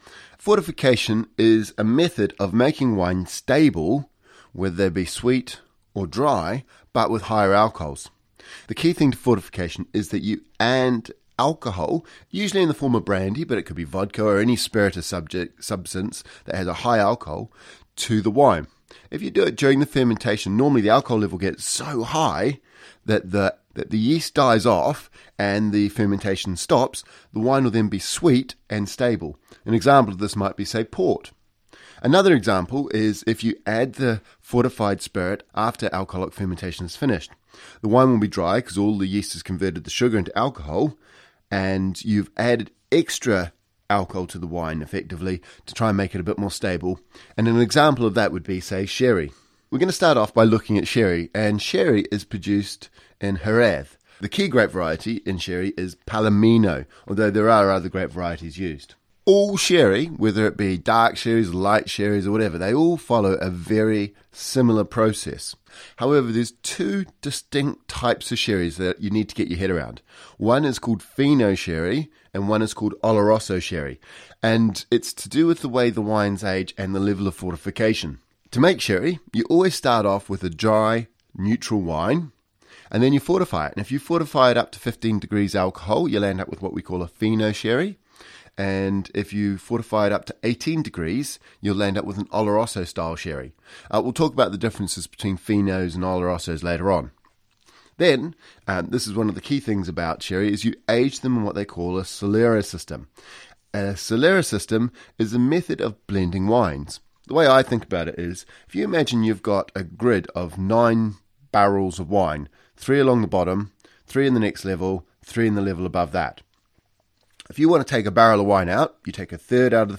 The music used for the UK Wine Show is Griffes de Jingle 1 by Marcel de la Jartèle and Silence by Etoile Noire.